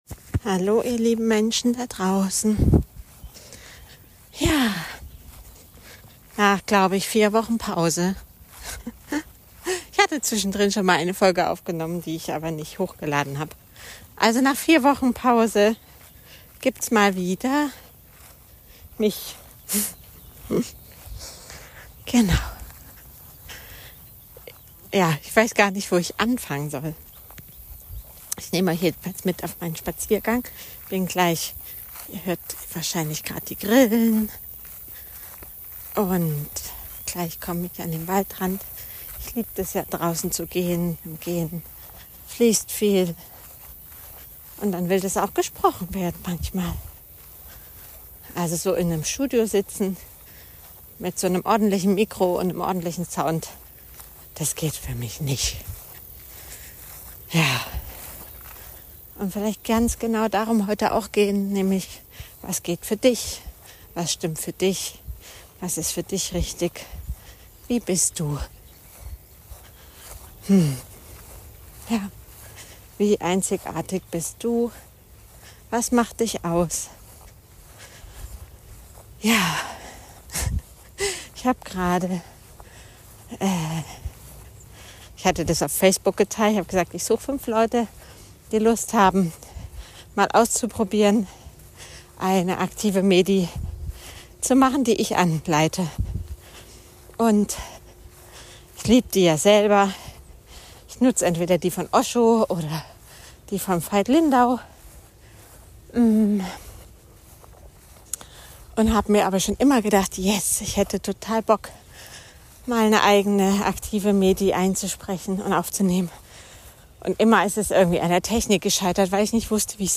Nach langer Pause gibts News vom Hochsitz am Waldrand